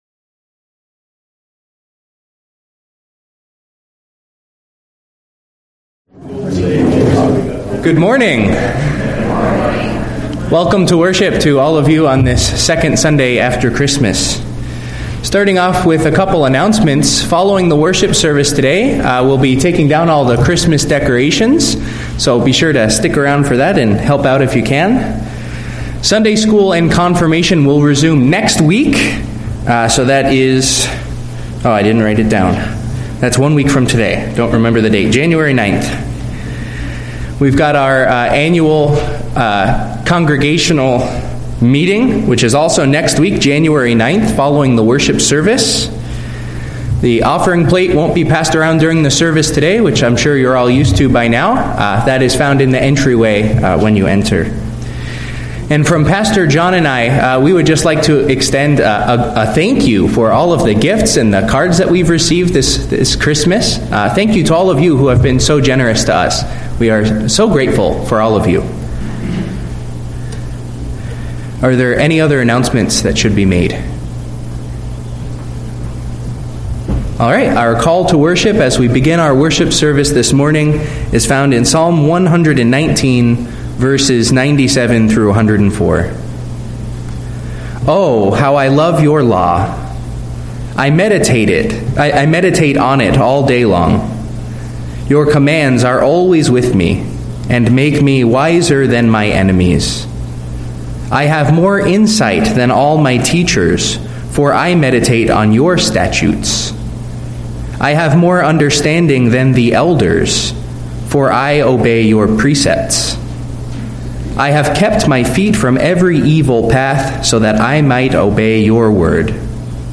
From Series: "Sunday Worship"